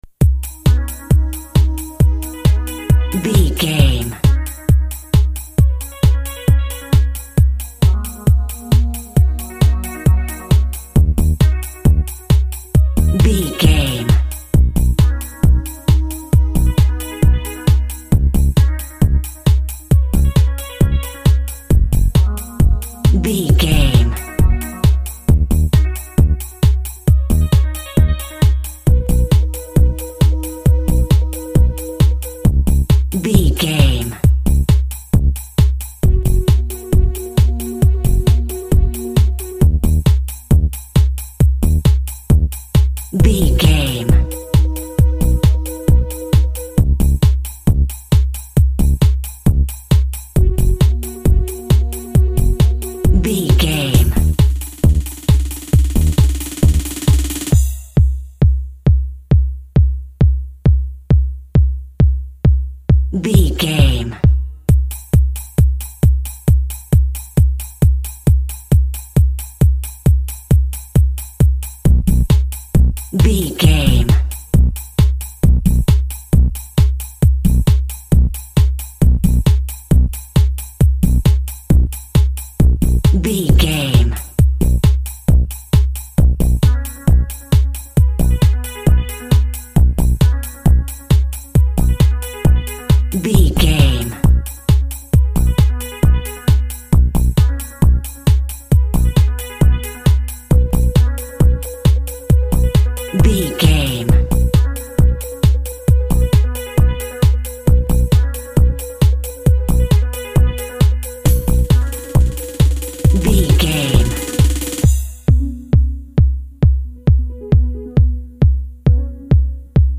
Industrial House Music Cue.
Fast paced
Ionian/Major
G♭
groovy
energetic
driving
synthesiser
drum machine
electric piano
electronic
synth lead
synth bass
Synth Pads